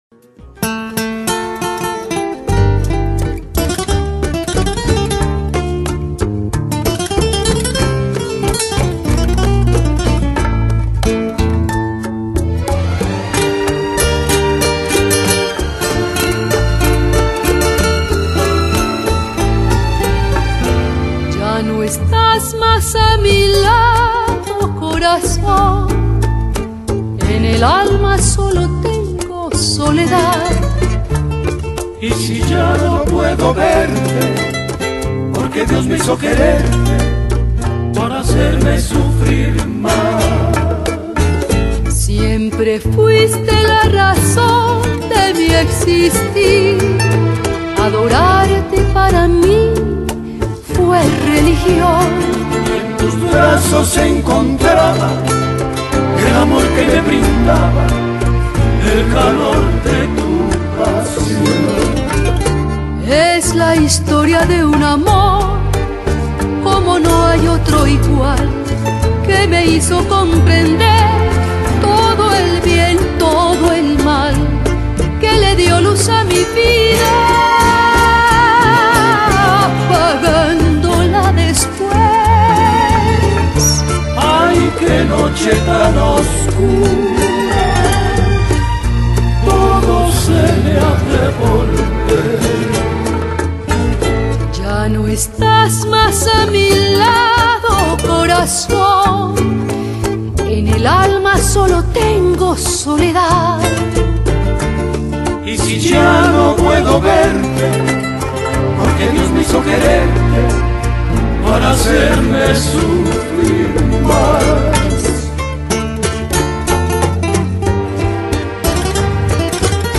2002-2011 Genre: Lounge | Lo-Fi | Chillout Quality